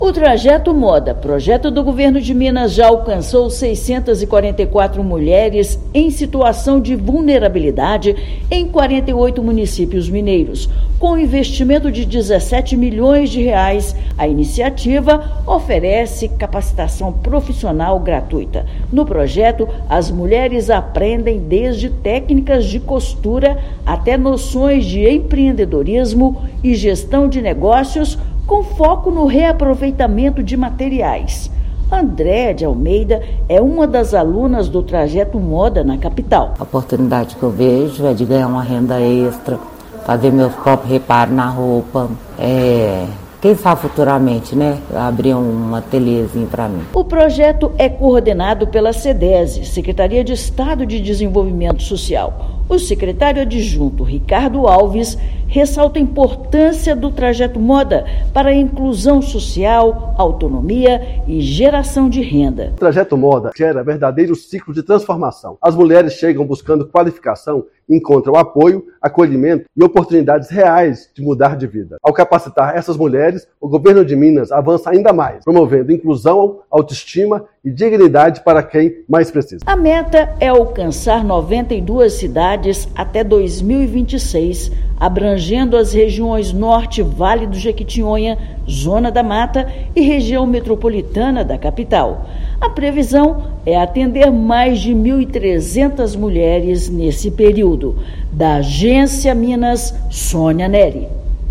Iniciativa oferece cursos gratuitos e transforma a vida de mulheres em vulnerabilidade. Ouça matéria de rádio.